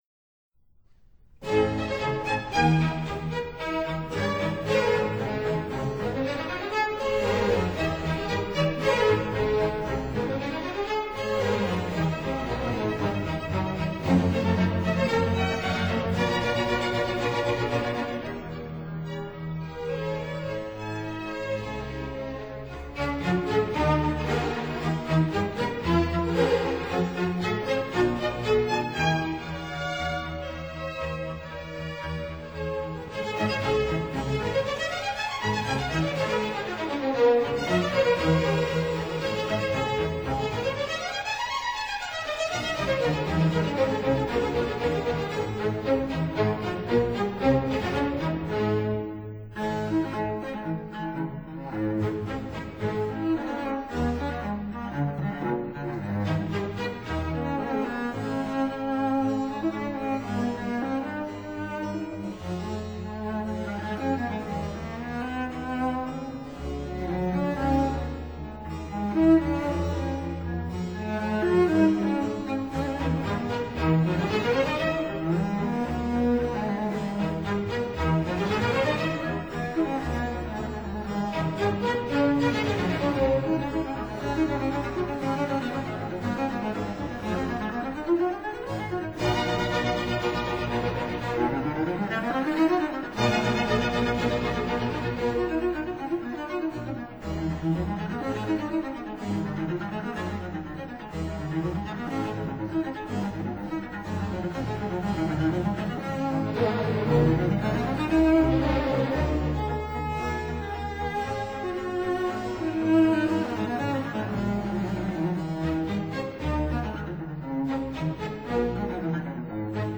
類型： 古典
cello